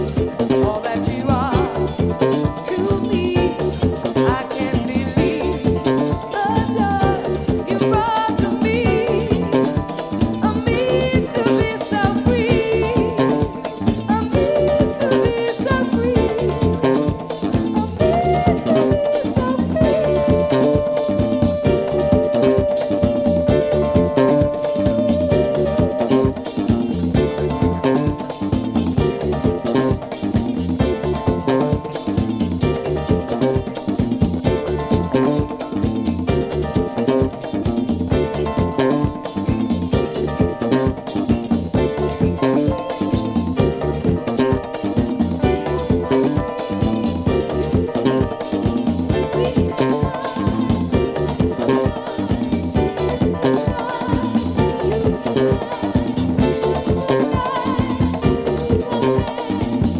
ジャンル(スタイル) RARE GROOVE / JAZZ FUNK / FUSION